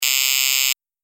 دانلود آهنگ زنگ 20 از افکت صوتی اشیاء
جلوه های صوتی
دانلود صدای زنگ 20 از ساعد نیوز با لینک مستقیم و کیفیت بالا